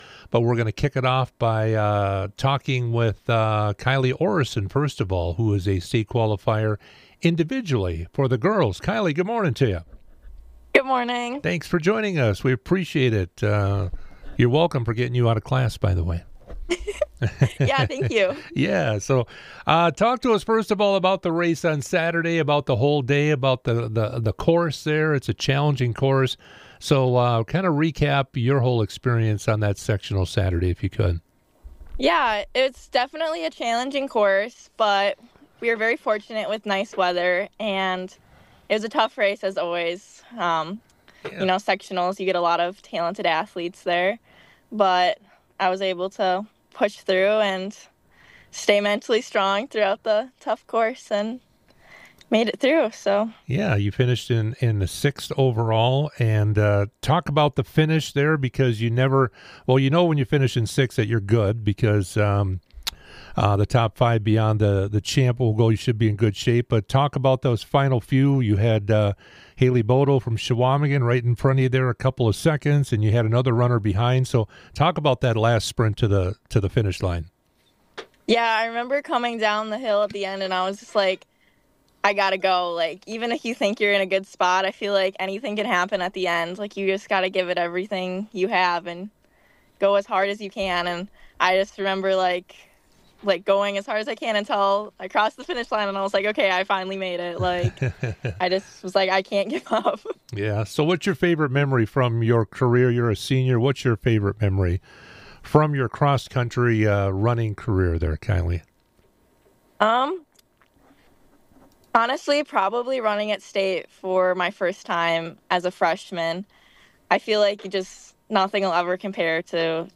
State Cross Country Interview: Rib Lake/Prentice - Civic Media
98q interviews